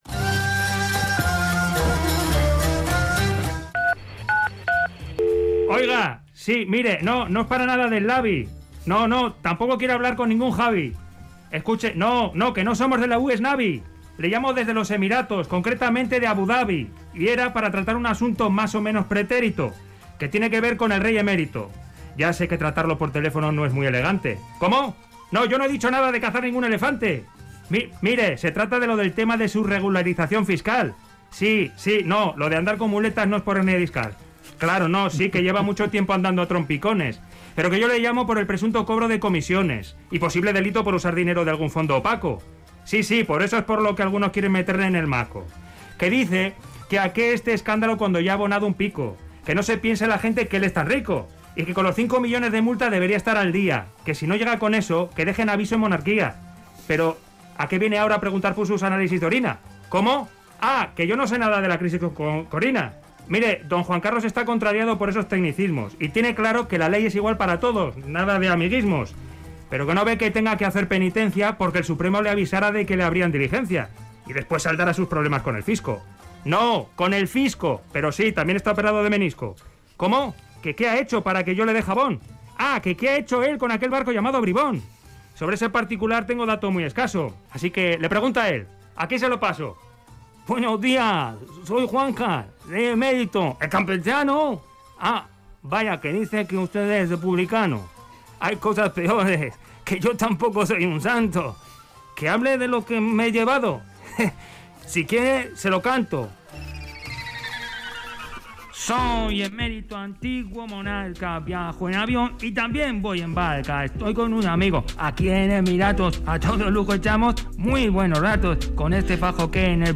Audio: En conexión telefónica desde los Emiratos, donde con algunos amigos echa buenos ratos; don Juan Carlos cree que no tiene que hacer penitencia porque el Supremo le avisara de que le abrían diligencia. Y, ante tal situación, les dedica una canción...